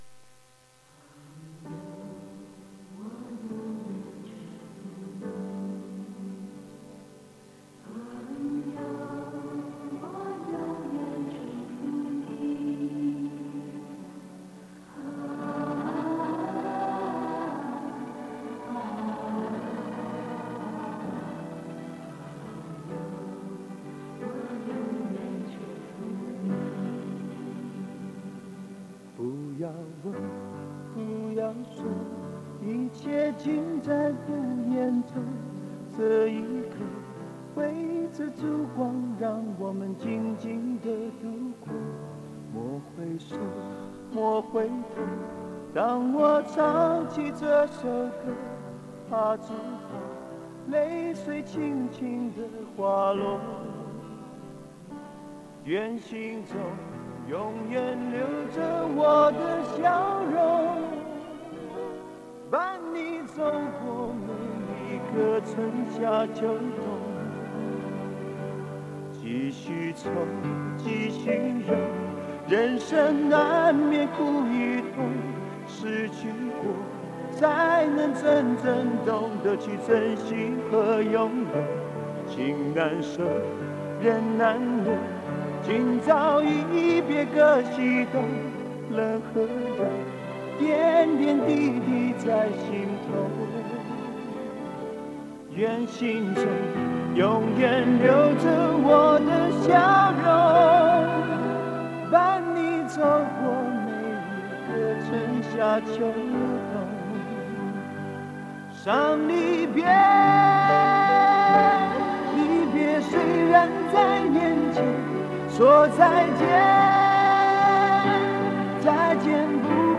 磁带数字化：2022-08-29
两段演唱以后，歌曲进入高潮，一连反复两遍，并且加入了女声合唱进行烘托，具有极强的感染力。